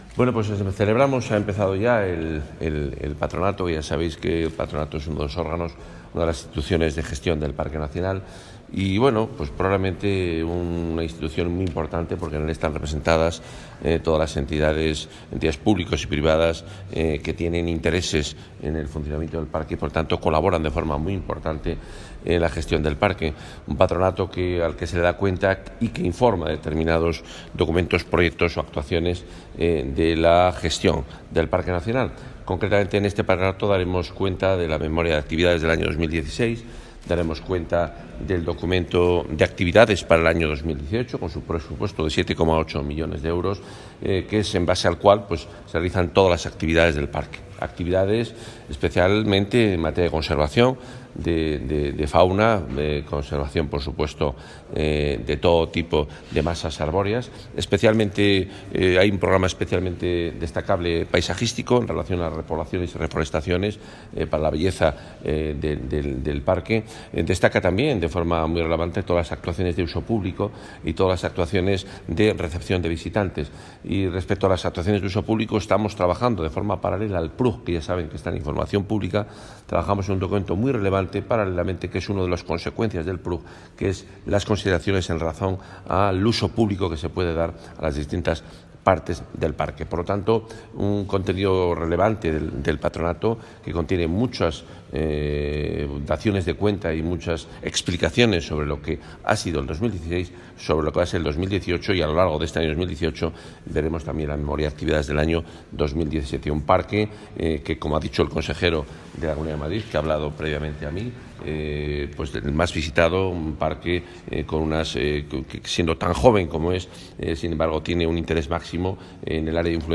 Declaraciones del consejero de Fomento y Medio Ambiente en el Patronato de Guadarrama.